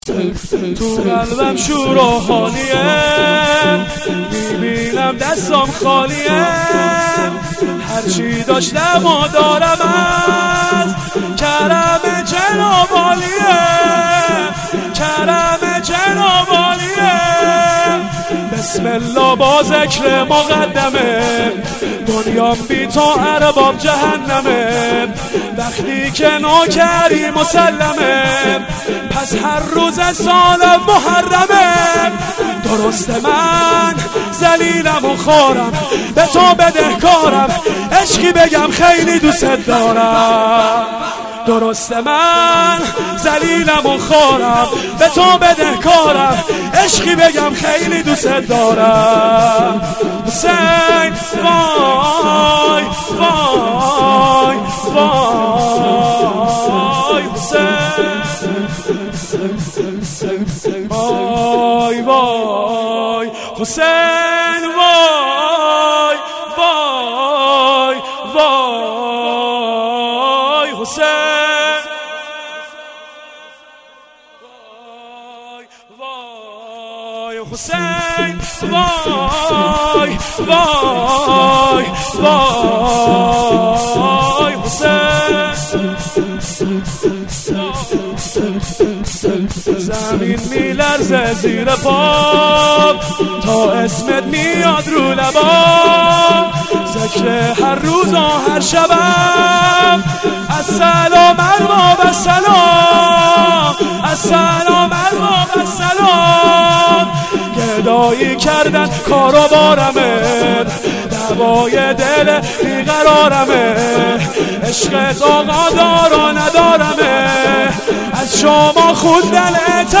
شب اول فاطمیه دوم
هیئت باب الحوائج نور
شور